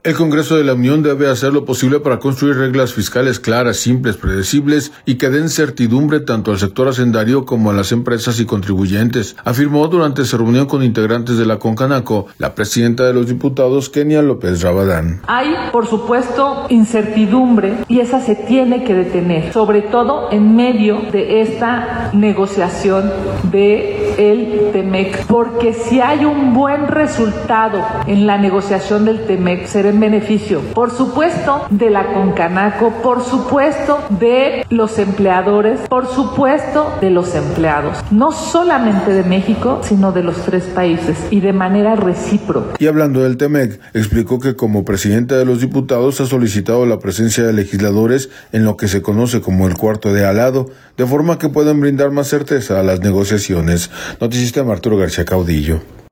El Congreso de la Unión debe hacer lo posible por construir reglas fiscales claras, simples, predecibles y que den certidumbre tanto al sector hacendario como a las empresas y contribuyentes, afirmó durante su reunión con integrantes de la Concanaco, la presidenta de los diputados, Kenia López Rabadán.